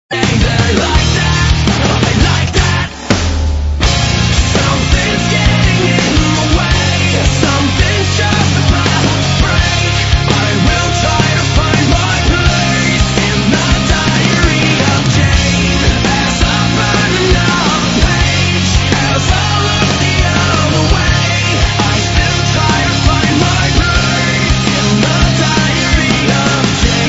Назад в Rock